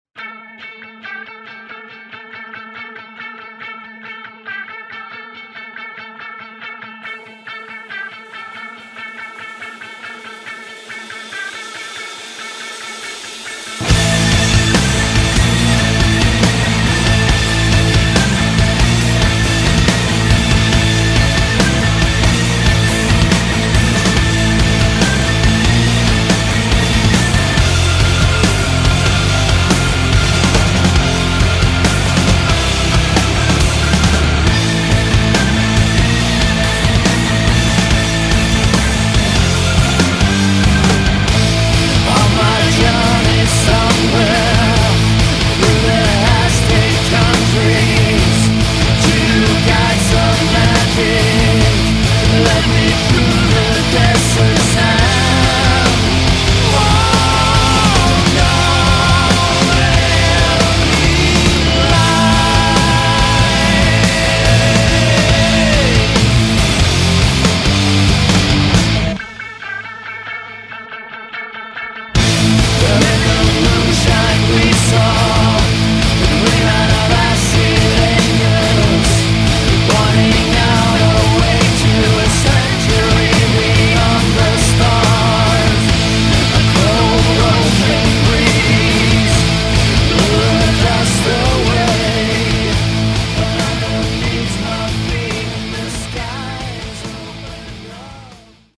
Nelle loro lunghe ed articolate composizioni